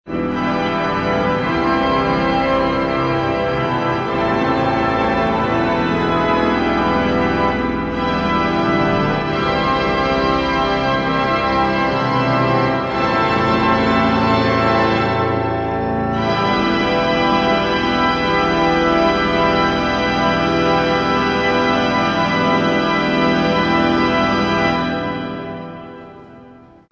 Concert sur l'orgue Aubertin de l'église Saint-Louis à Vichy
Les extraits montrent quelques échantillons des sonorités particulières de l'orgue.